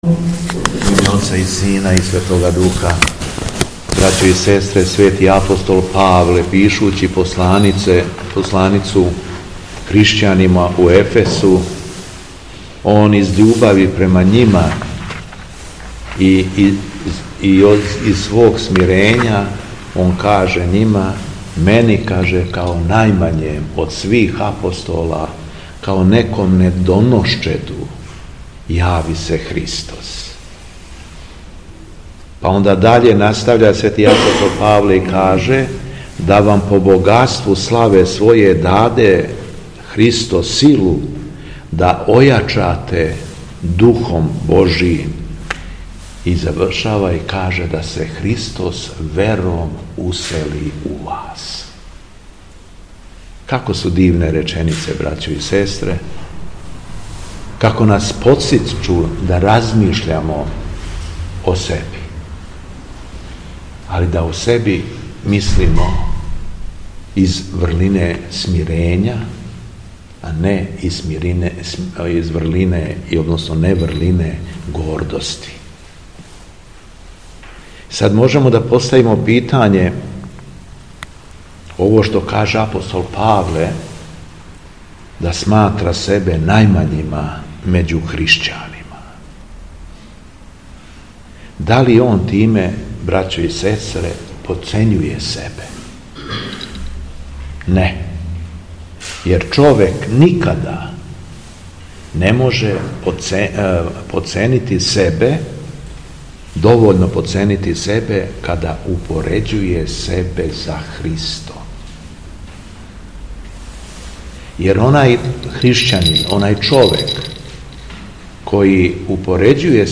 Беседа Његовог Преосвештенства Епископа шумадијског г. Јована
Након прочитаног јеванђеља по Марку Владика се обратио верном народу: